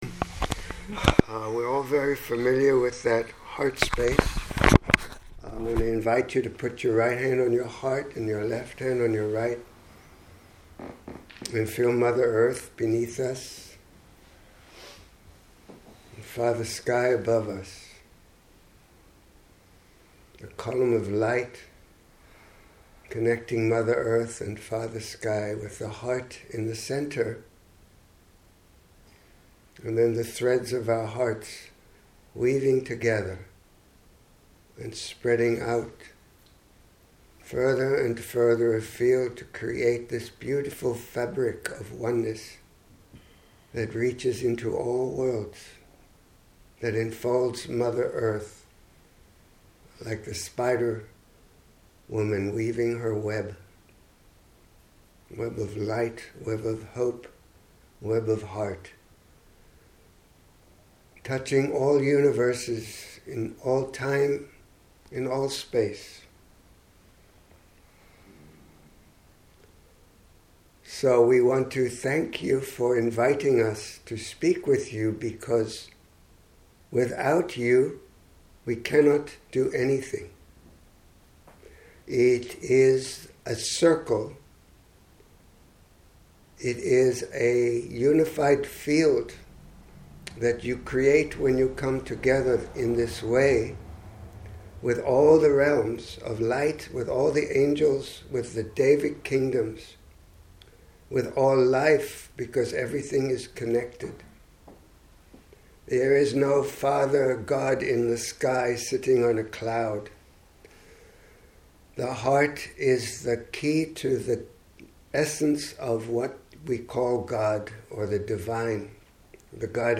Here is a recording of the Channeling session I shared with the group at the Walking In Beauty 3 day ceremony in The Netherlands during the Autumn Equinox this September. Because it covers so much basic ground, I felt it is applicable to everyone and the answers to individual questions cover issues of interest to us all, even though they were specific.